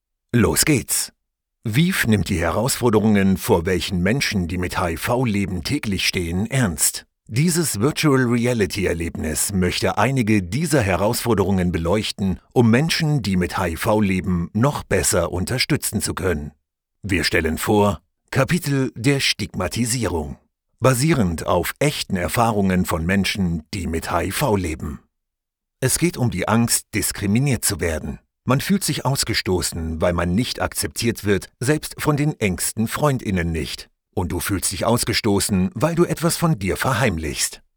Medical Narration
I sound dynamic, fresh, powerful, cool and confident and I’m ready to record in my studio 24/7 – also via remote control: Source Connect Now, Session Link Pro, ipDTL, Skype, Microsoft Teams.
microphone: Neumann TLM 49